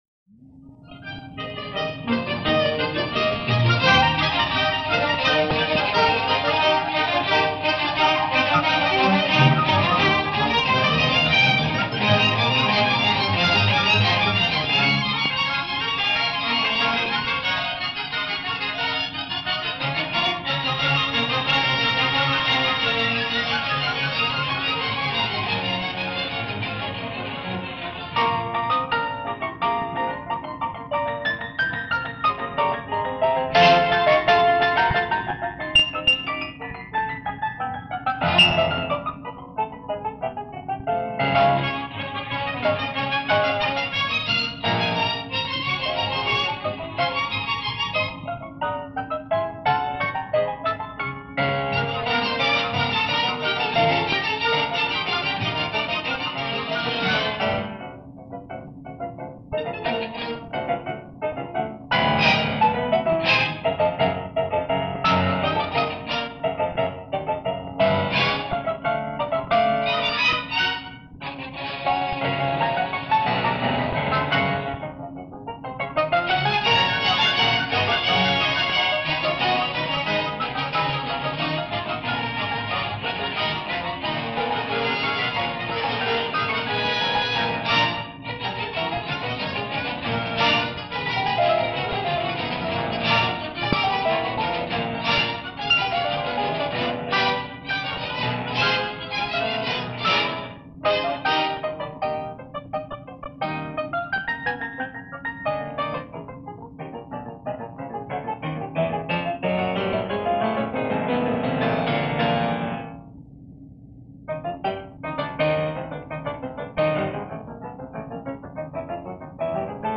piano
Radio Paris transmission